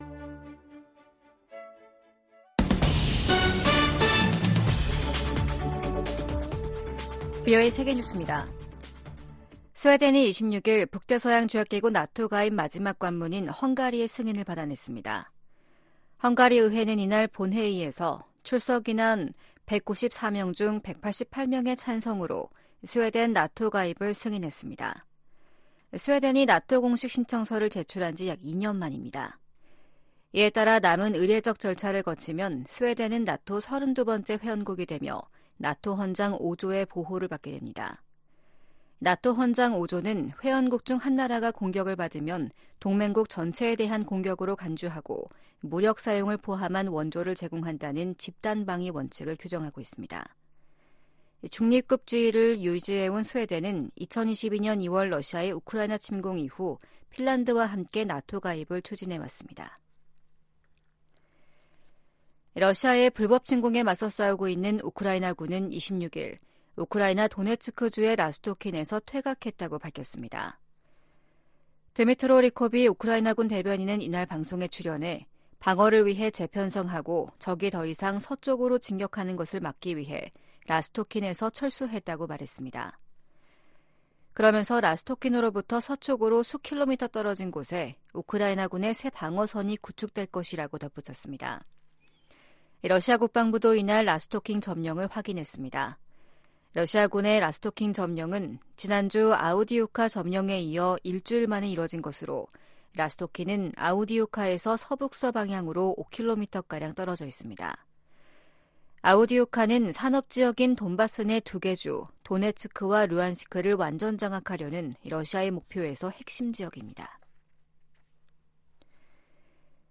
VOA 한국어 아침 뉴스 프로그램 '워싱턴 뉴스 광장' 2024년 2월 27일 방송입니다. 주요7개국(G7) 정상들이 우크라이나 전쟁 2주년을 맞아 북한-러시아 탄도미사일 거래를 규탄했습니다. 미국 정부의 대규모 러시아 제재에 대북 무기 수출에 관여한 러시아 물류 회사와 한국 기업 한 곳이 제재 명단에 올랐습니다. 미국과 중국의 북핵 대표가 최근 화상 회담을 갖고 한반도 문제를 논의했습니다.